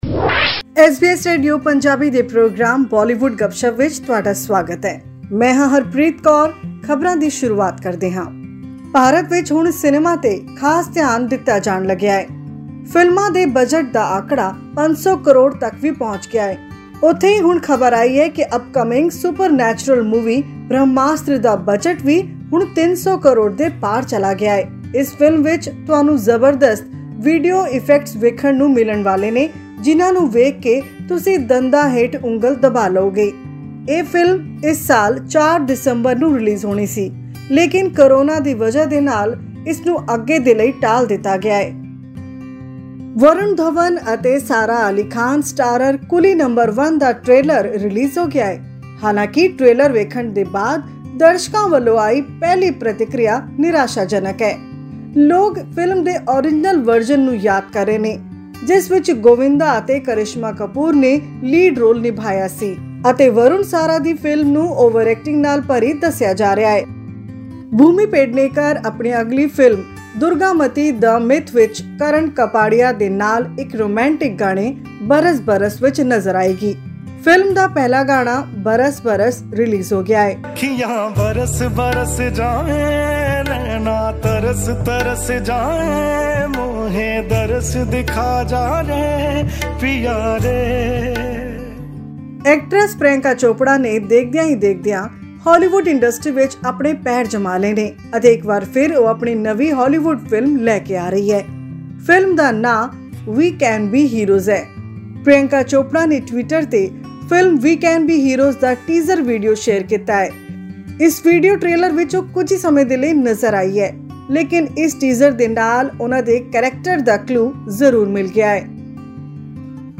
And Anushka Sharma- Virat Kohli are expecting a baby in January. This and much more in our weekly news bulletin from Bollywood.